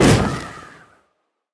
Minecraft Version Minecraft Version 25w18a Latest Release | Latest Snapshot 25w18a / assets / minecraft / sounds / mob / breeze / wind_burst2.ogg Compare With Compare With Latest Release | Latest Snapshot
wind_burst2.ogg